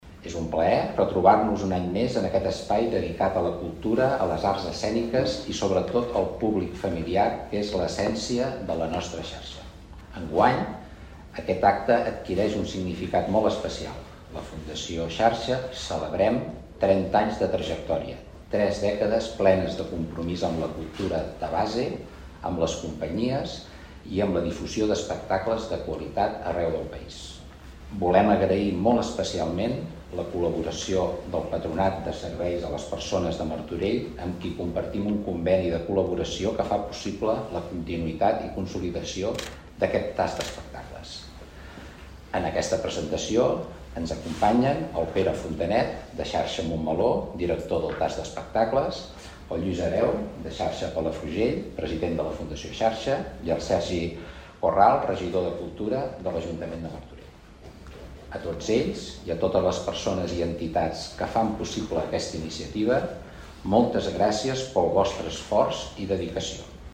Roda de Premsa presentació 14è Tast d'Espectacles Familiars